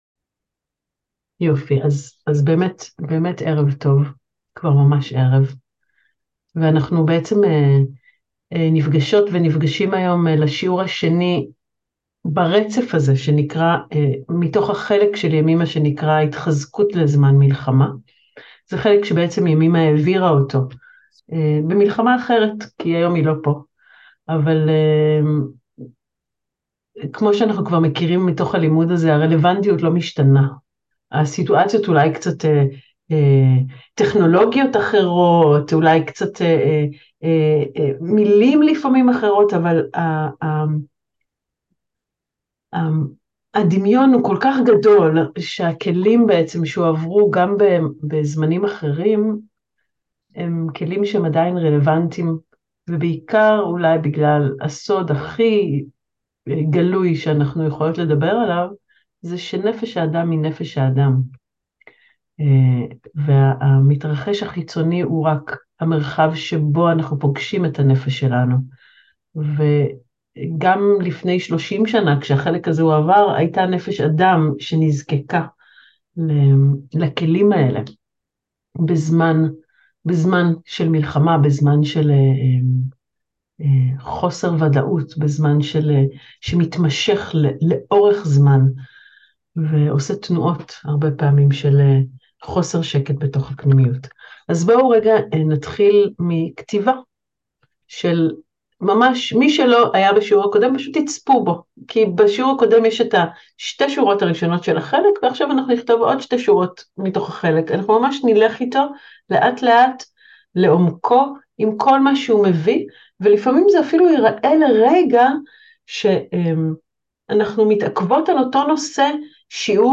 מתוך השיעורים הפתוחים